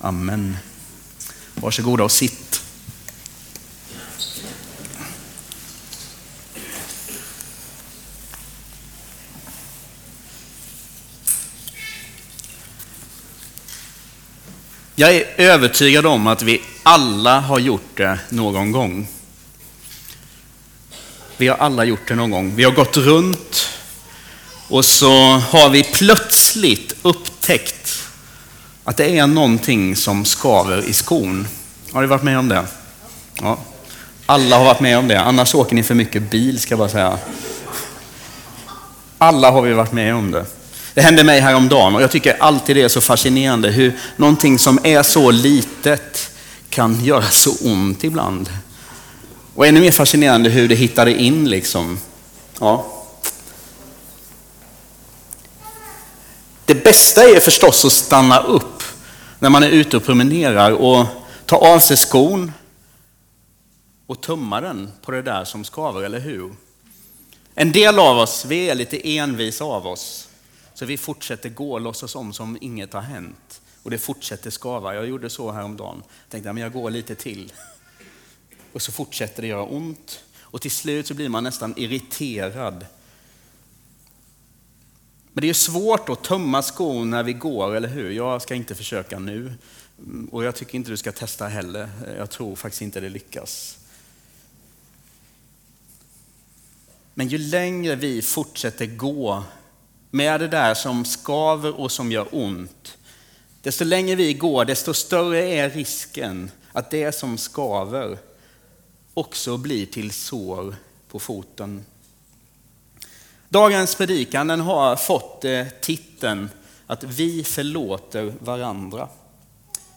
A predikan from the tema "Vi."